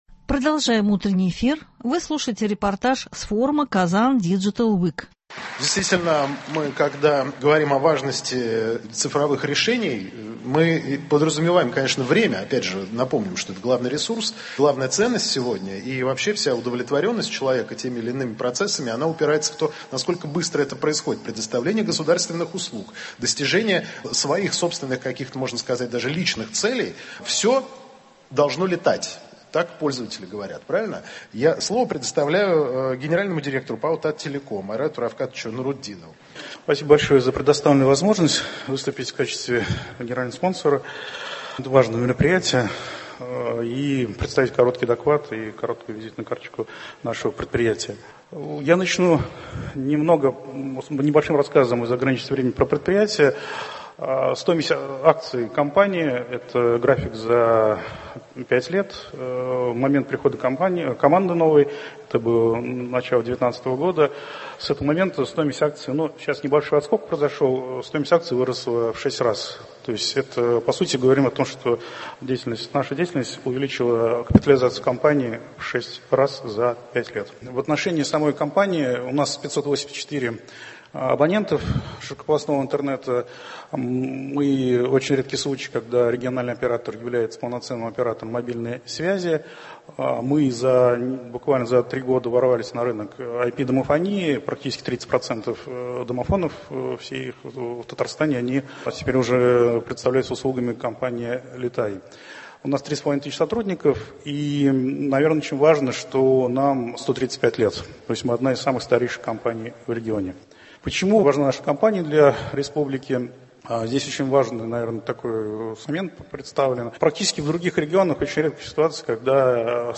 Репортаж с форума Kazan Digital Week.